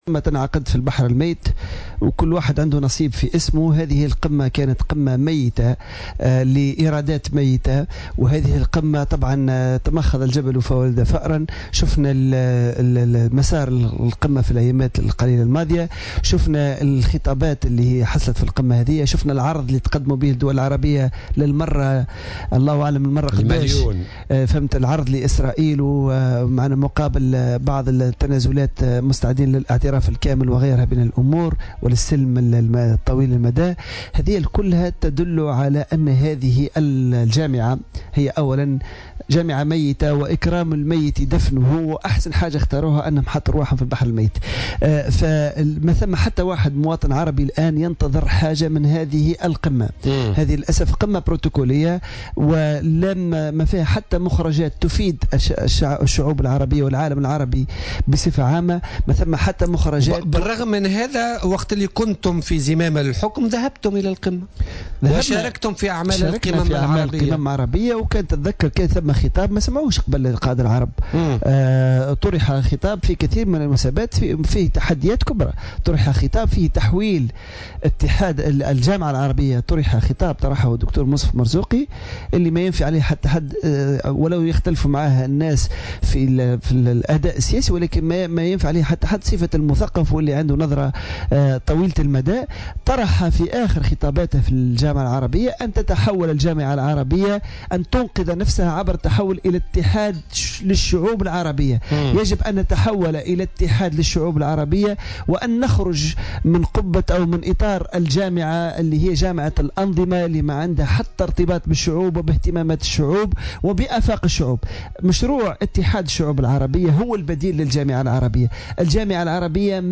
وأضاف الدايمي، ضيف برنامج "بوليتيكا" اليوم أن القمة العربية المنعقدة بالأردن هي قمة "بروتوكولية" ولا ينتظر منها قرارات أو مخرجات تفيد الشعوب العربية.